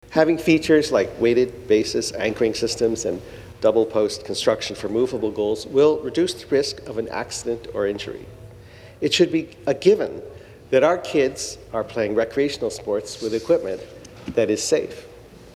Ted Hsu is the member for Kingston & The Islands and spoke on the safety concerns.